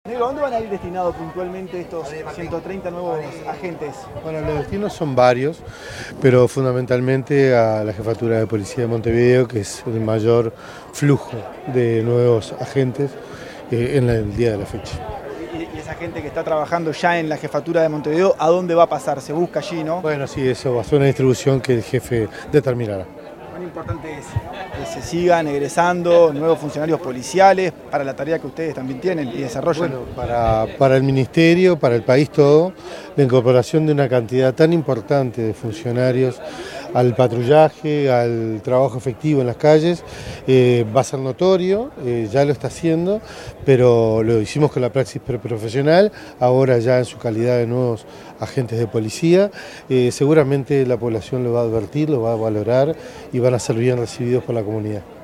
Declaraciones del ministro del Interior, Carlos Negro
Declaraciones del ministro del Interior, Carlos Negro 11/11/2025 Compartir Facebook X Copiar enlace WhatsApp LinkedIn Tras la ceremonia de egreso de la Promoción LXXII de la Escuela Policial de la Escala Básica, denominada Honor, Disciplina y Denuedo, el ministro del Interior, Carlos Negro, diálogó con los medios de prensa.